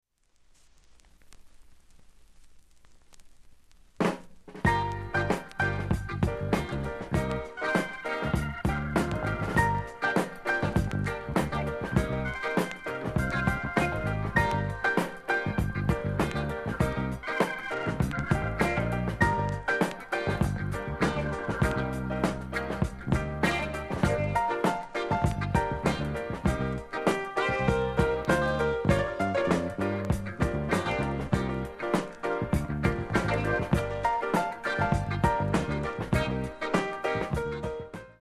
音質目安にどうぞ ほか問題なし音質良好全曲試聴済み。